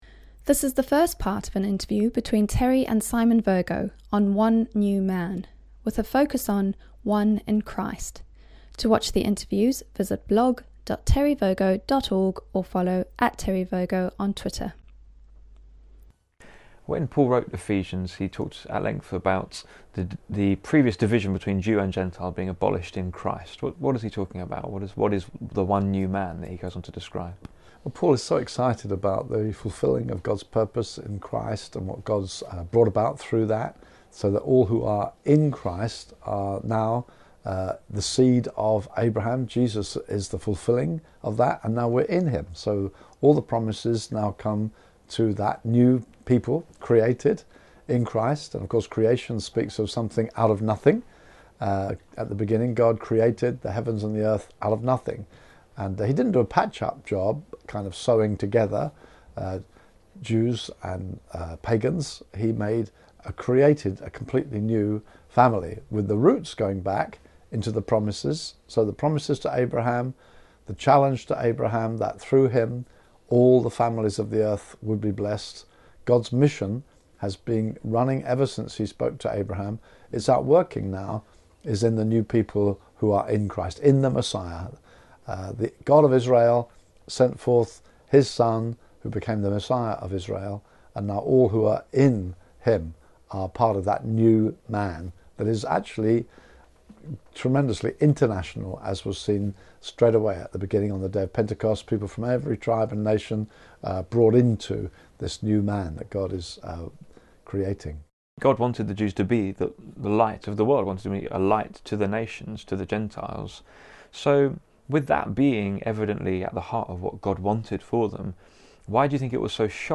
Interview+14a.+One+in+Christ.mp3